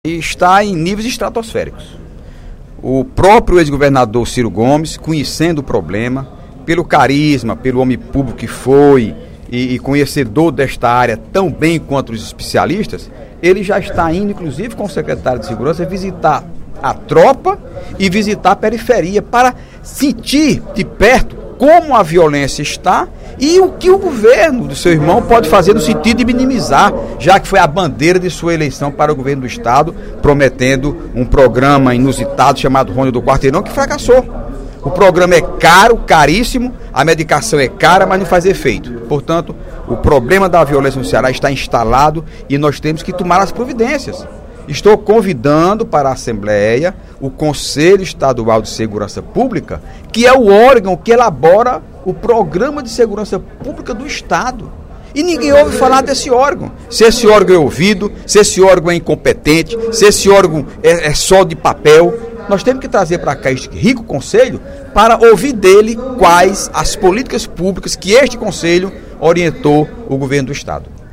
O deputado Heitor Férrer (PDT) fez um apelo, nesta terça-feira (02/04), durante o primeiro expediente da sessão plenária, para que o Governo do Estado reveja o pregão realizado para contratação de serviços de enfermagem no Ceará. De acordo com o deputado, uma cooperativa de técnicos de radiologia venceu o pregão por oferecer menor taxa de administração, mas não conta com os profissionais necessários para a realização do trabalho.